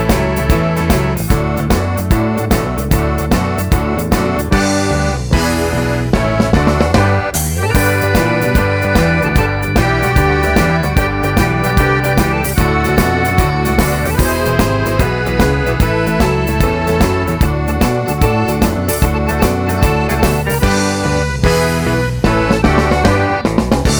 T.V. Themes